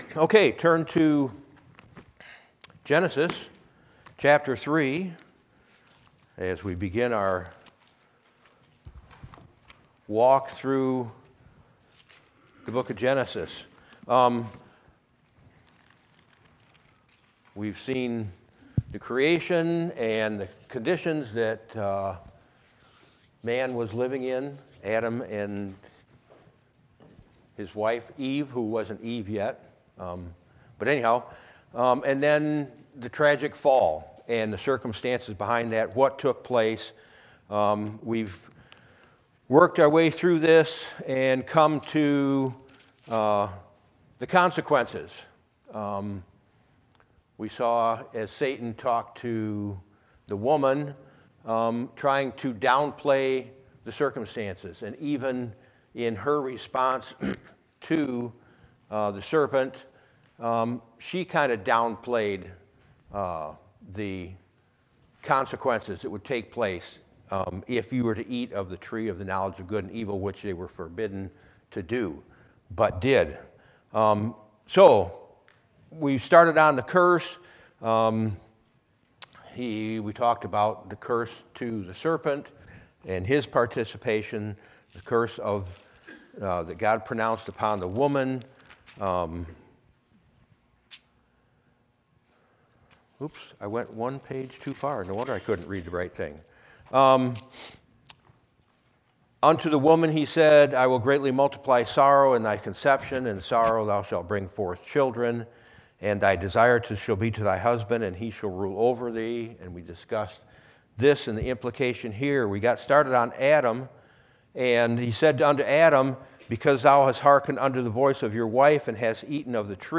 Scripture - Genesis 28:1-15 When - Sunday School Service